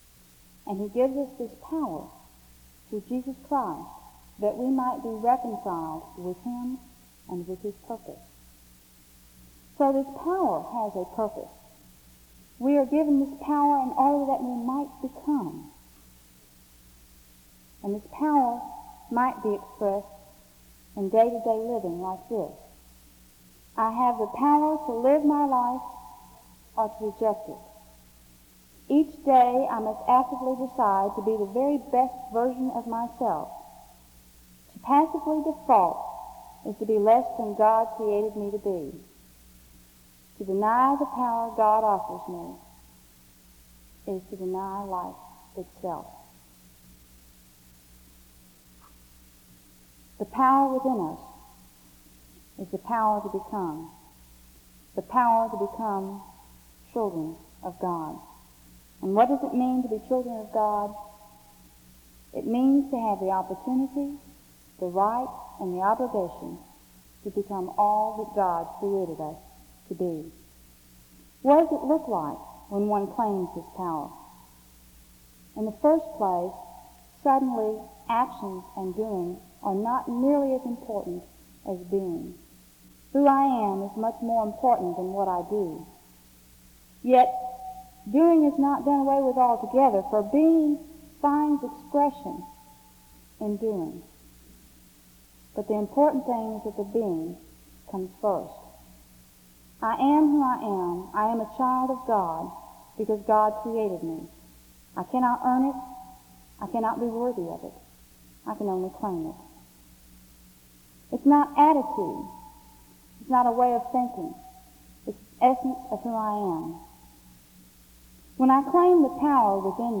The service starts with a scripture reading and prayer from 0:00-2:51. An announcement is made from 2:54-3:11.